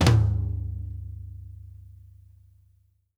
FLAMFLOOR3-L.wav